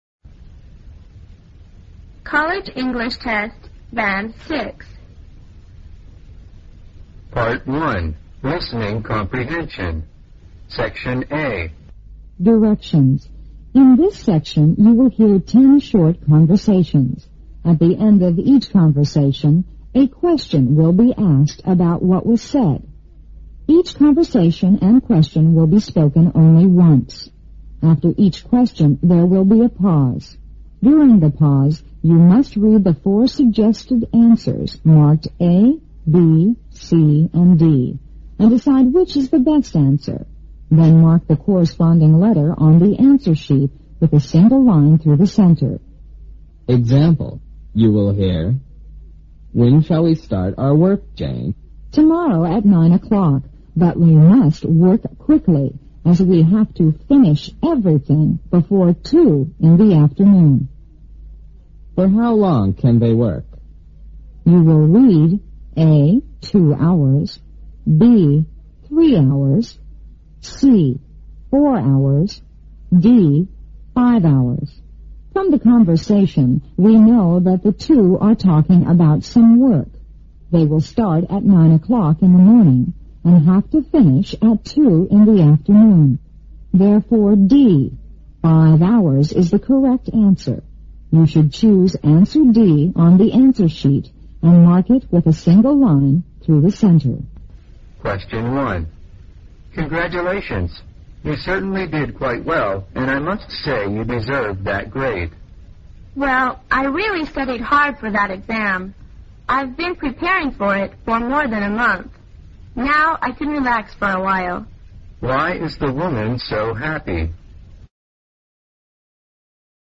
1999年1月大学英语六级考试听力附试题和答案MP3音频下载,1999年1月大学英语六级考试试题及参考答案Part I Listening Comprehension (20 minutes) Section A Directions: In this section you will hear 10 short conversations.